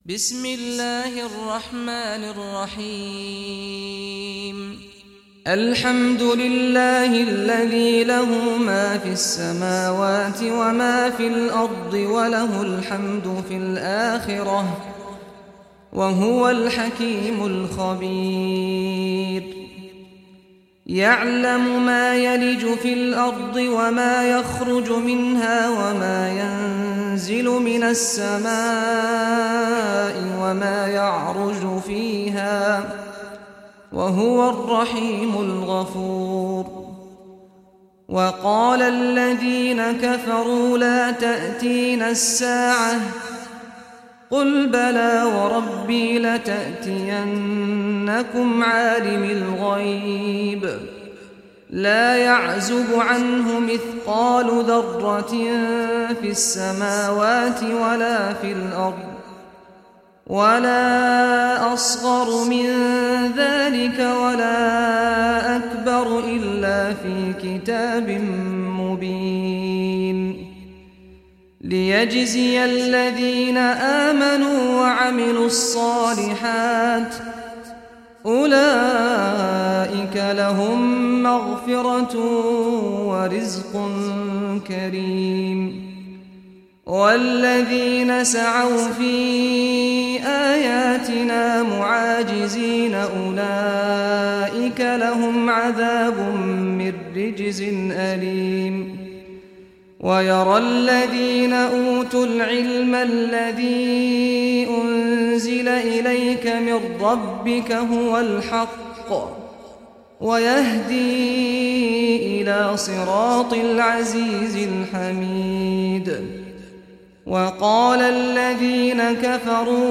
Surah Saba Recitation by Sheikh Saad al Ghamdi
Surah Saba, listen or play online mp3 tilawat / recitation in Arabic in the beautiful voice of Sheikh Saad al Ghamdi.